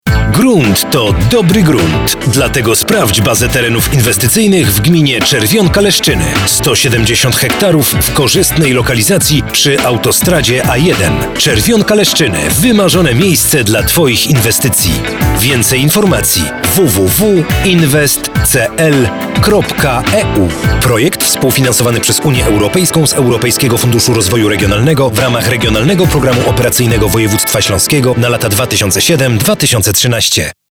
W maju na antenie ogólnopolskiej rozgłośni radiowej RMF FM można było usłyszeć  spoty promujące tereny inwestycyjne naszej gminy.